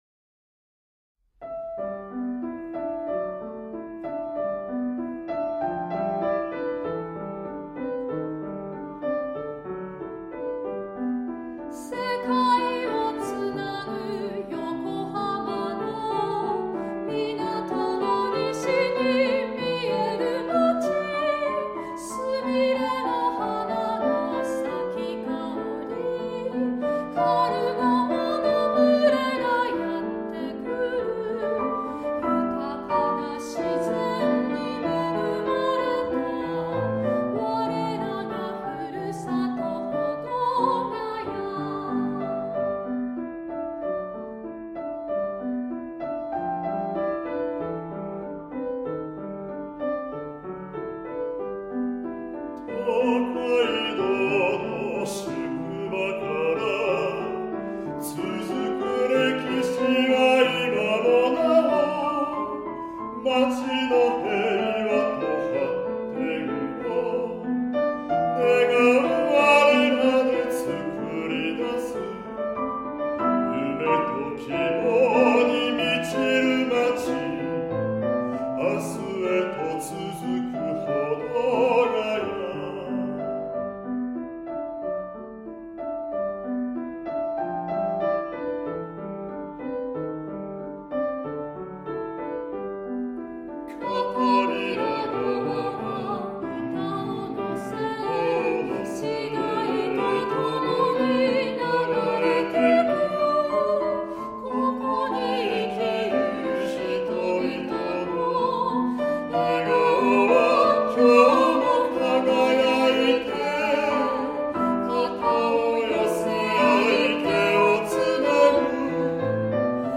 Duo